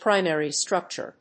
プライマリストラクチュア